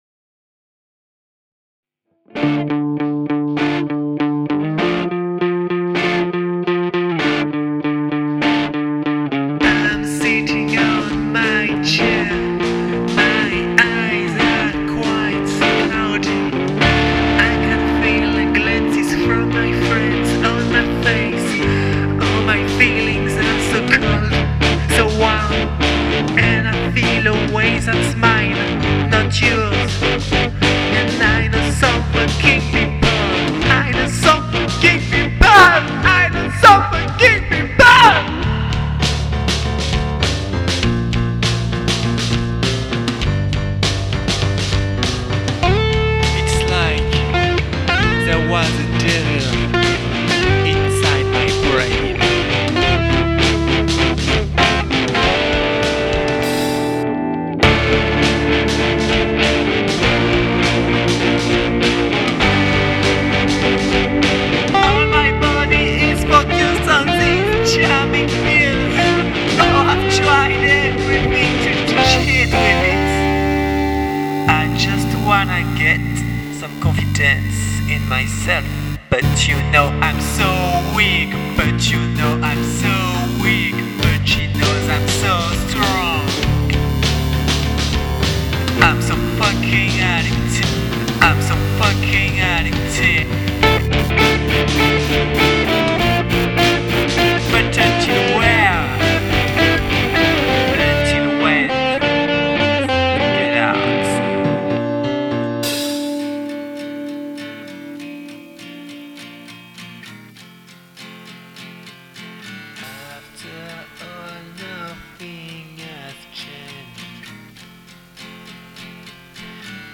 Niveau mix ça va pas.
Le mixage est pas top et j'ai cru entendre quelques canards, mais t'affirme clairement ton style.
j'ai l'impression en l'écoutant que le guitariste joue dans une salle de bain carrelée jusqu'au plafond, que le batteur est au grenier et toi (si c'est toi qui chante) qui hurle dans mes oreilles a travers un gobelet en plastique.